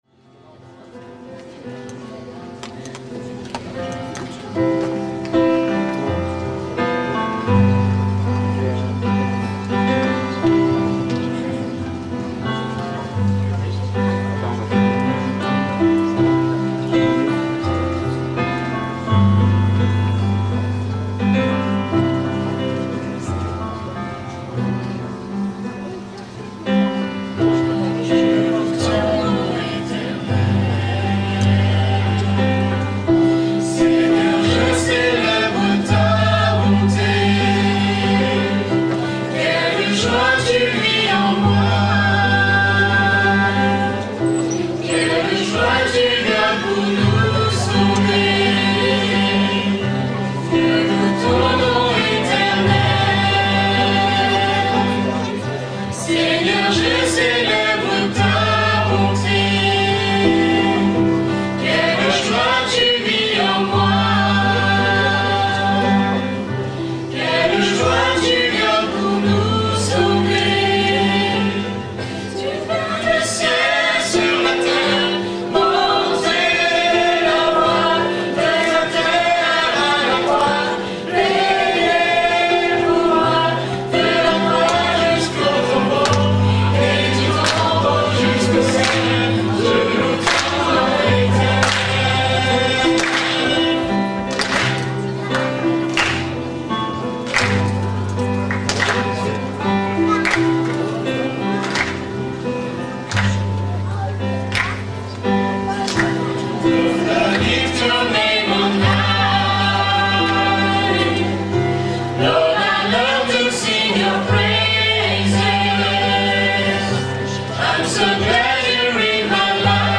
Voici quelques extraits audio de la soirée récréative !